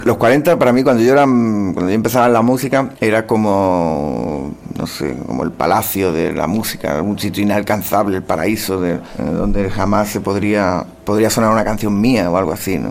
Radio show
Resposta sobre la Cadena 40 Principales del cantant Alejandro Sanz
Programa presentat per Tony Aguilar.